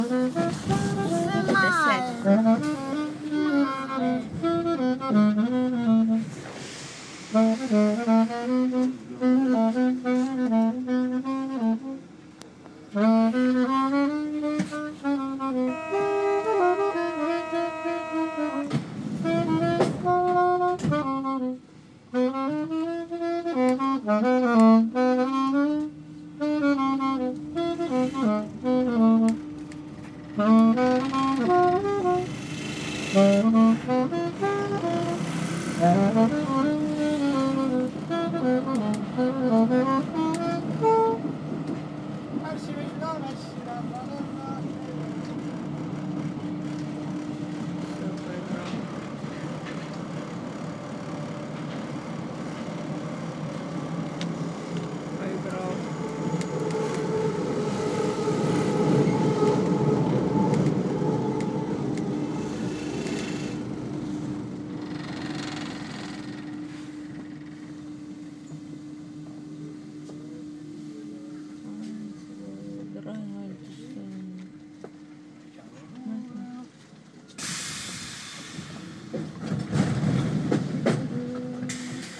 Paris metro